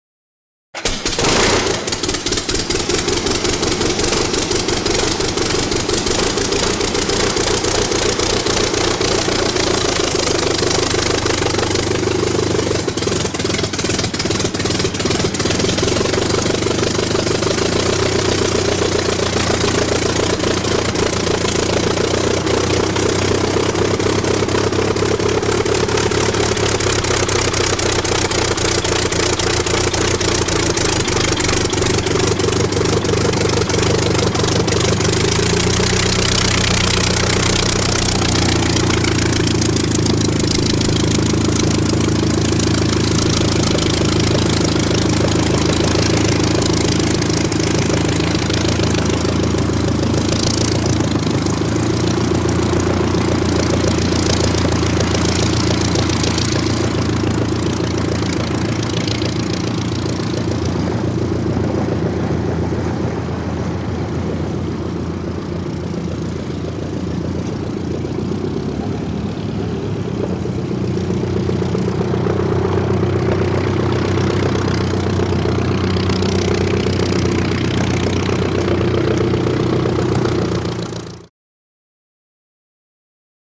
Bi Plane | Sneak On The Lot
Prop Plane; Start / Taxi; Bi Plane In Hanger Starts Up And Taxis Out.